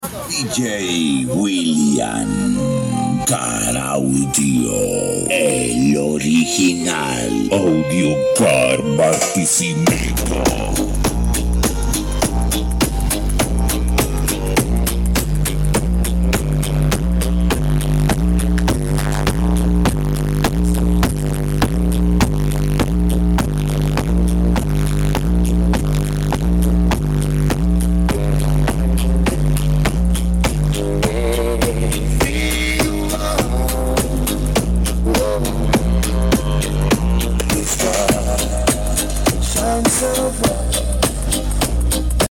Double 15 Hla Built Subwoofers Sound Effects Free Download